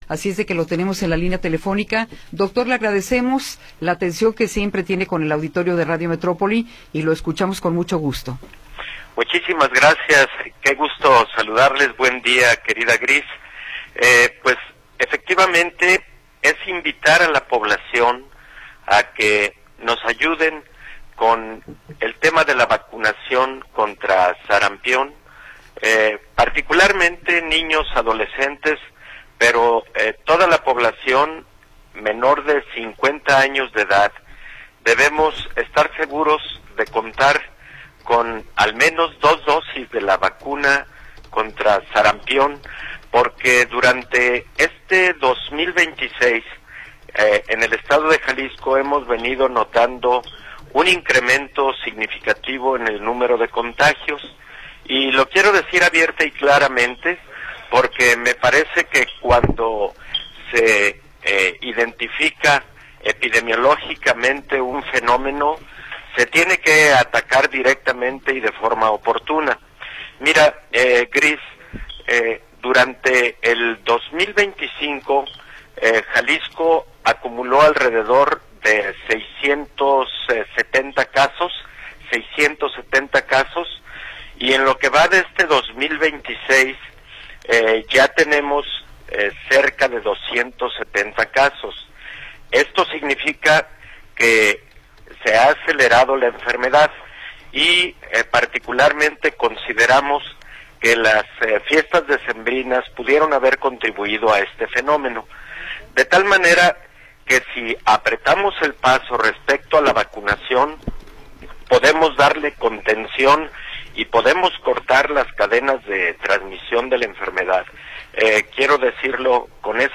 Entrevista con el Dr. Héctor Raúl Pérez Gómez